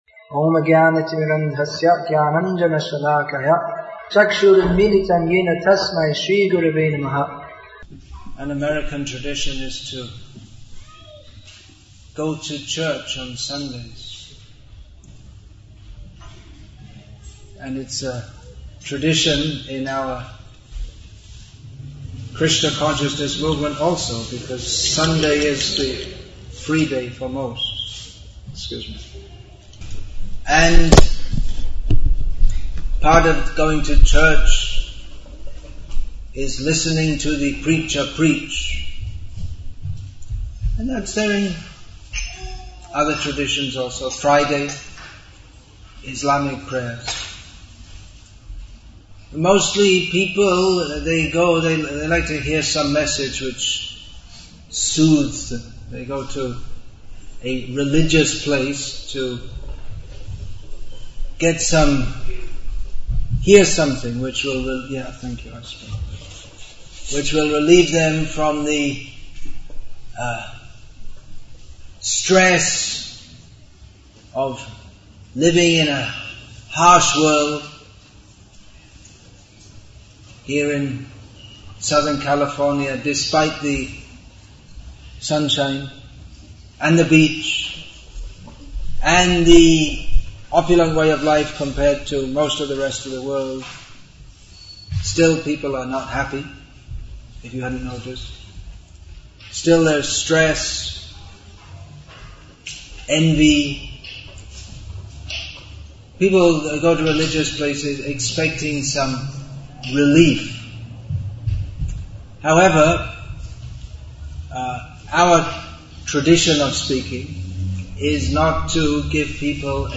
A Different Kind Of Sunday Sermon